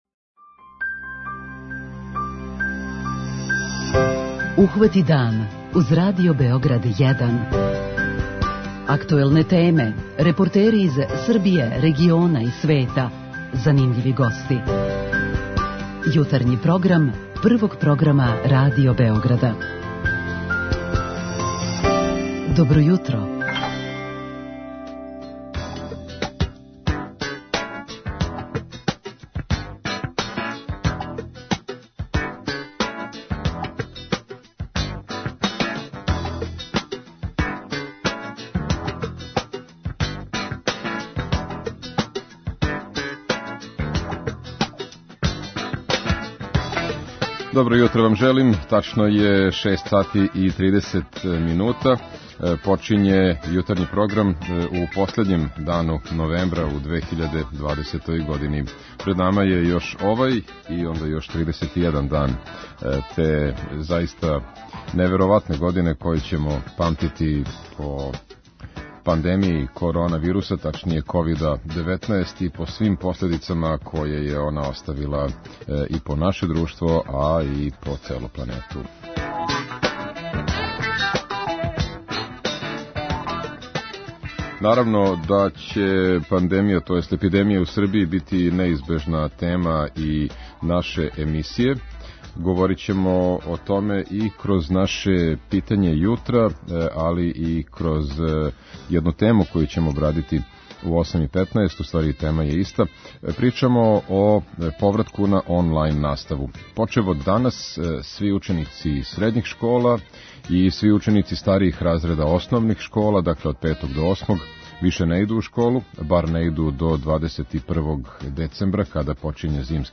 Исто ово питање поставићемо и слушаоцима у редовној рубрици "Питање јутра". преузми : 37.78 MB Ухвати дан Autor: Група аутора Јутарњи програм Радио Београда 1!
Пооштрене су и мере у вртићима, па ћемо чути прилог о томе како на те промене реагују и деца и васпитачи.